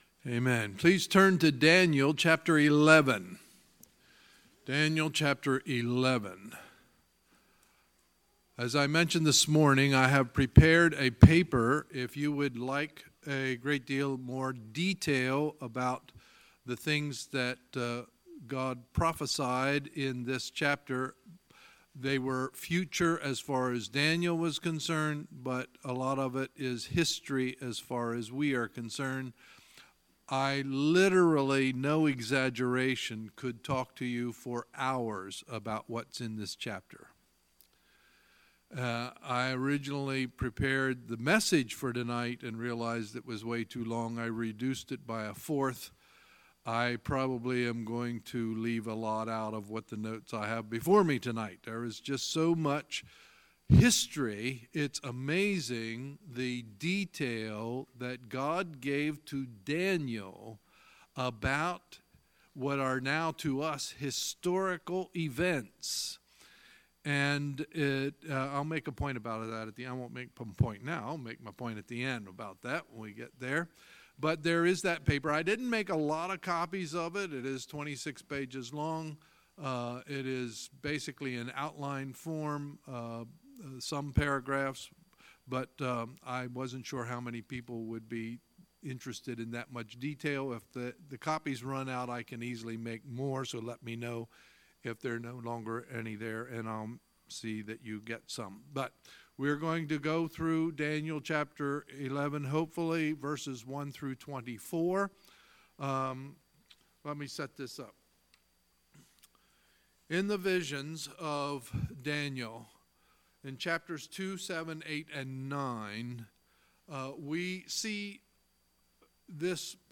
Sunday, August 26, 2018 – Sunday Evening Service